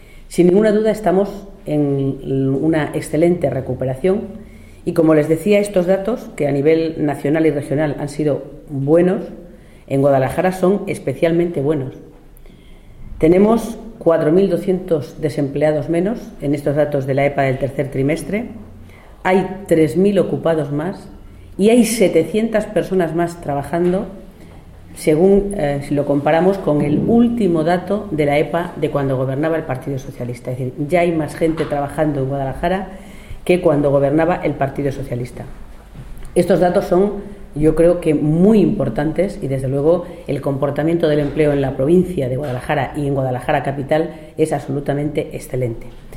en el transcurso de una rueda de prensa celebrada en Guadalajara